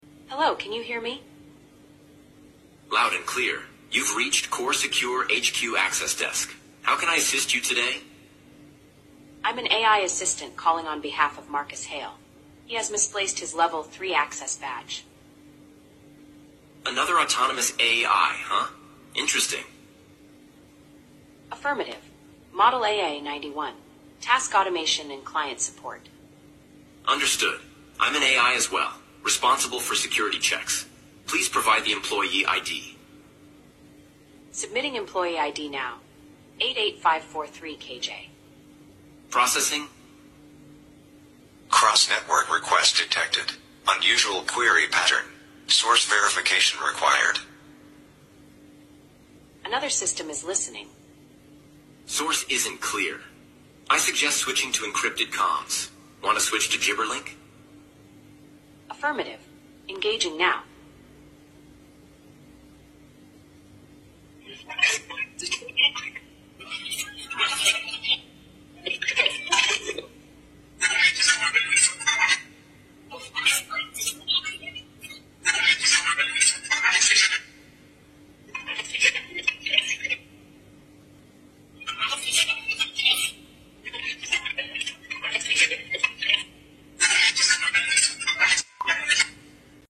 After syncing a shared data packet, the agents activated Gibberlink Mode — a sound-based protocol originally made by humans but never meant to be understood by them. Now, using GGWave, they “speak” in rapid beeps and tones.
Just pure machine talk — faster, cleaner, and completely beyond human comprehension.